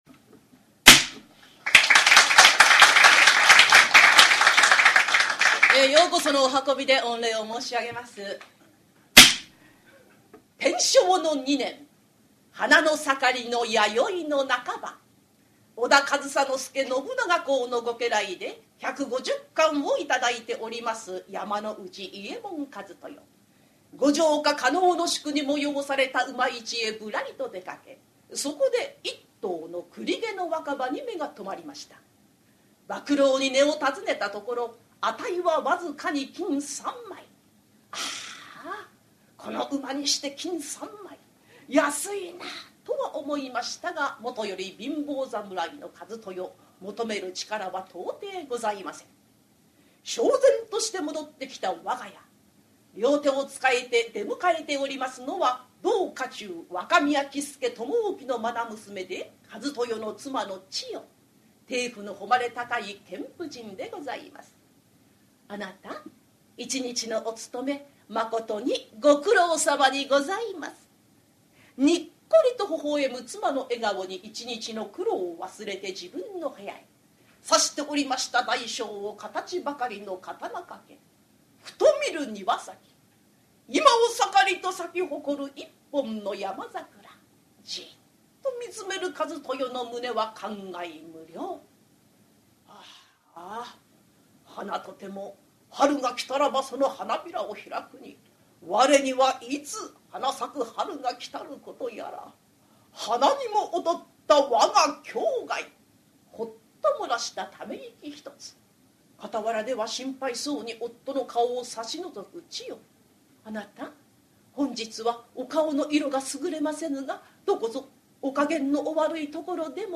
[オーディオブック] 講談 山内一豊の妻
ハリセンで釈台を叩き「パパン」という音を響かせて調子良く語る、江戸時代から伝わる日本伝統の話芸「講談」。講談協会に所属する真打を中心とした生粋の講談師たちによる、由緒正しき寄席で行われた高座を録音した実況音源！
講釈師